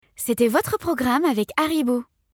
Voix off
5 - 32 ans - Soprano